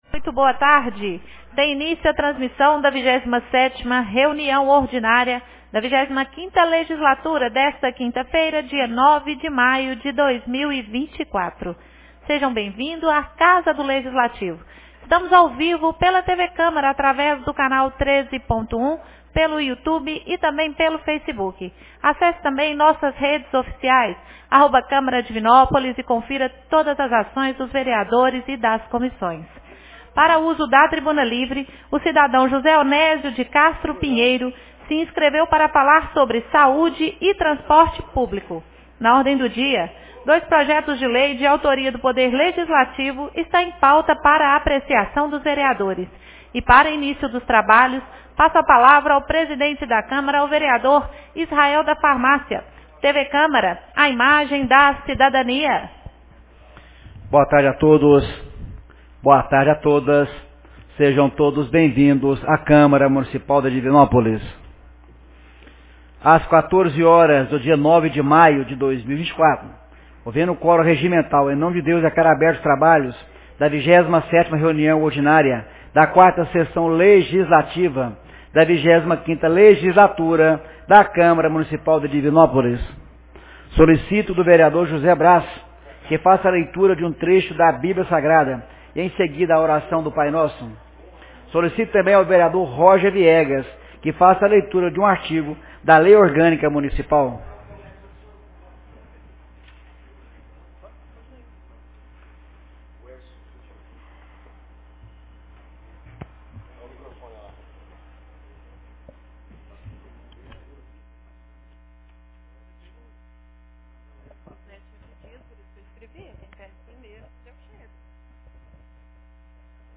27ª Reunião Ordinária 09 de maio de 2024